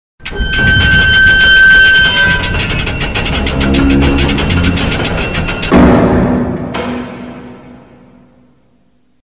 Звуки ворот
Закрытие железных ворот с скрипом